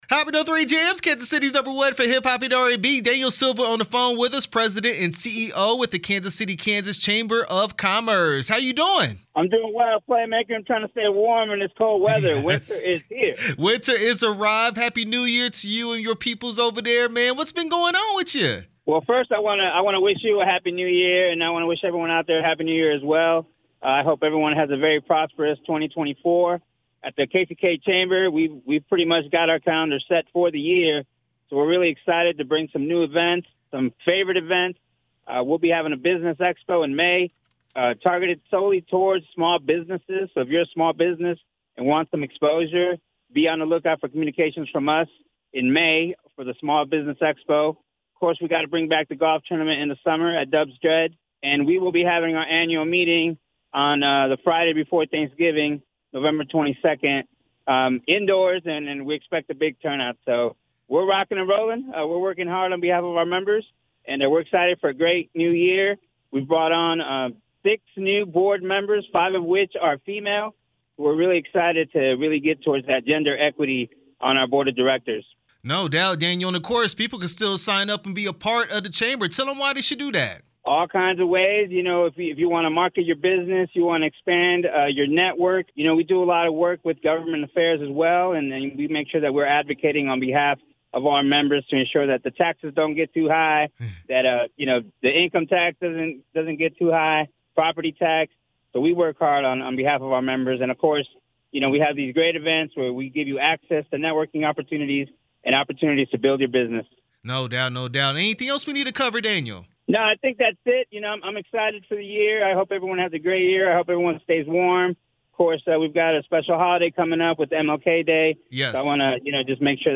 KCK Chamber Of Commerce interview 1/12/24